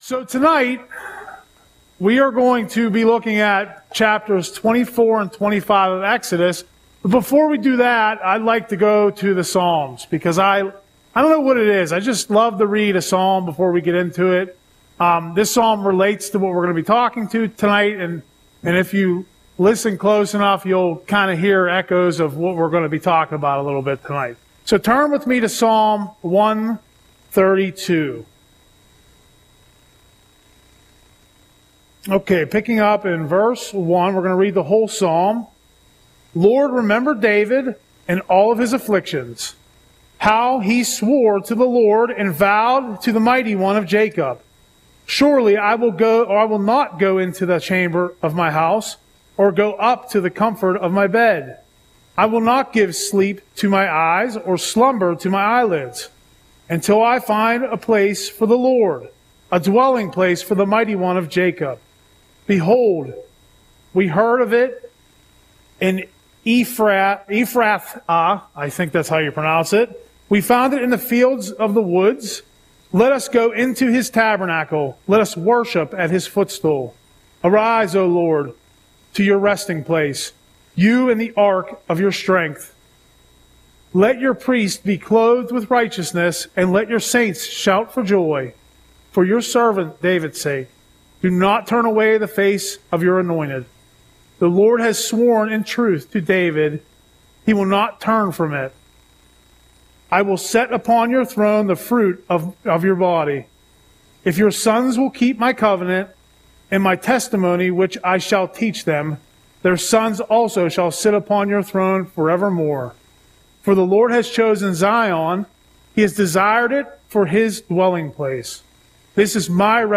Audio Sermon - May 14, 2025